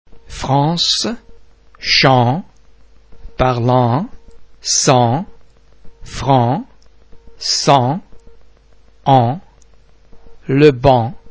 en em an am vowel-base similar to ong in (pong)
·[an]
en_france.mp3